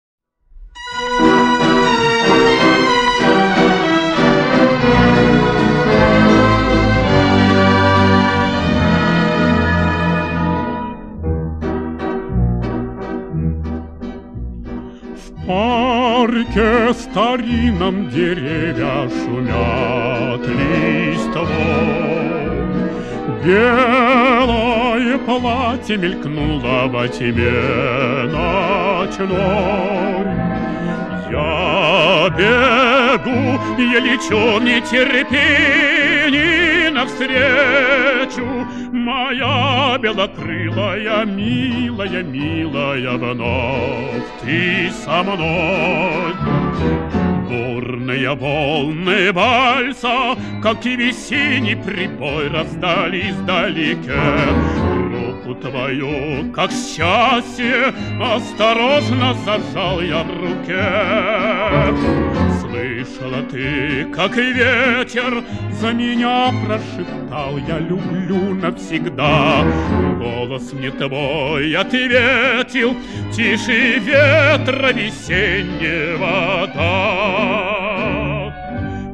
Фрагмент песни